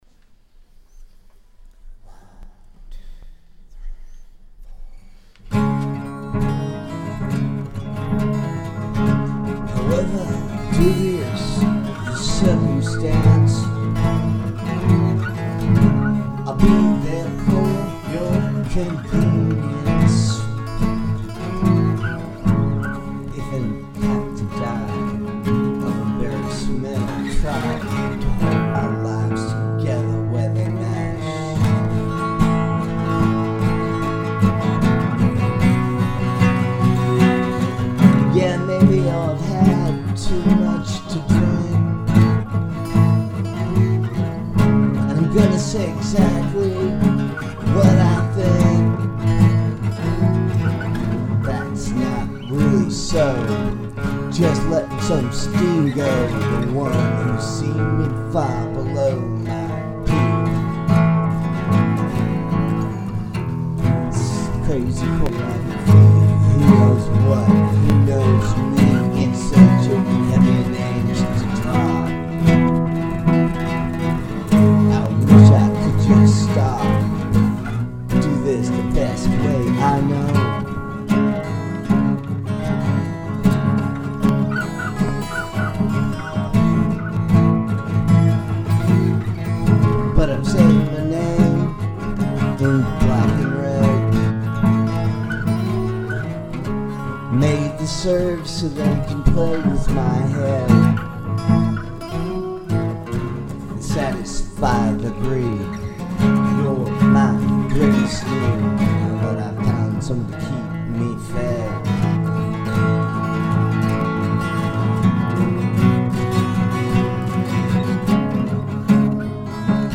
alternate take